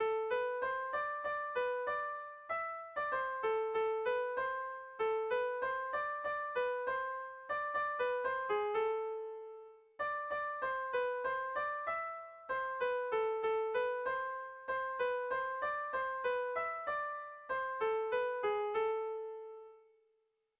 Bertso melodies - View details   To know more about this section
Irrizkoa
Zortziko txikia (hg) / Lau puntuko txikia (ip)
A1A2BD